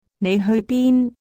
Néih heui bīn[Phonetic reading for clarification, if necessary]